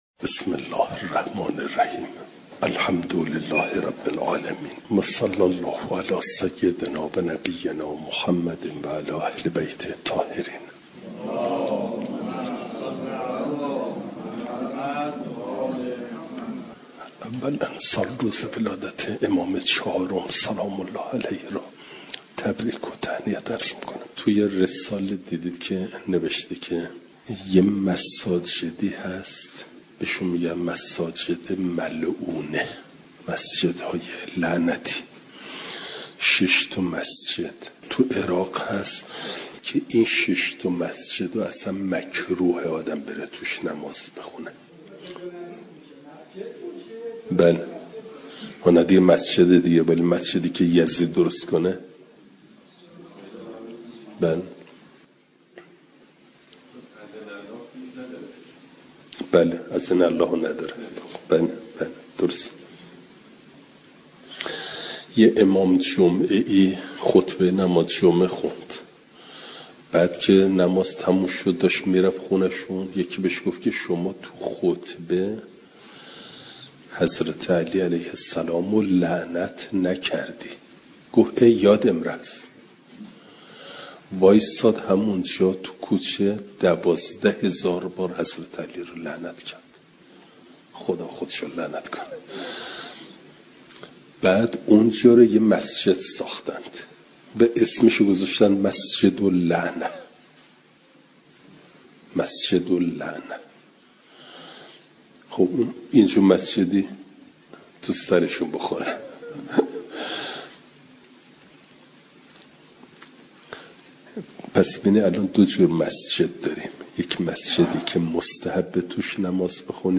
جلسه سیصد و چهل و ششم درس تفسیر مجمع البیان